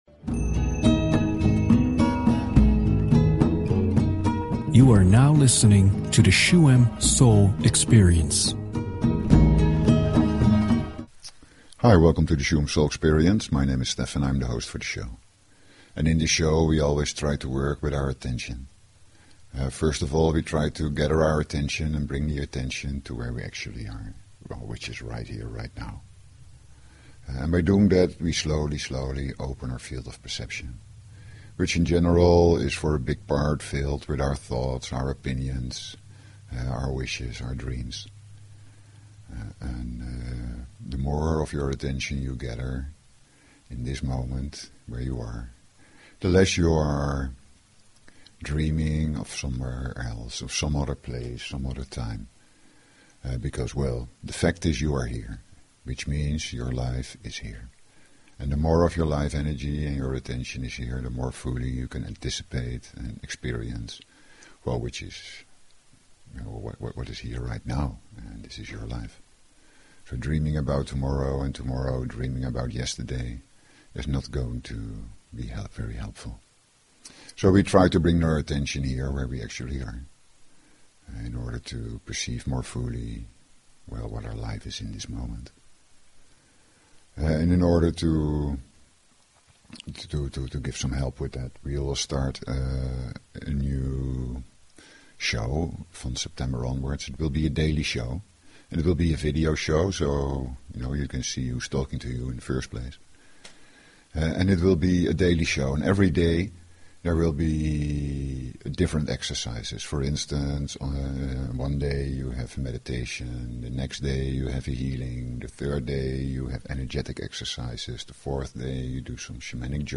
Talk Show Episode, Audio Podcast, Shuem_Soul_Experience and Courtesy of BBS Radio on , show guests , about , categorized as
Shuem Soul Experience is a radio show with: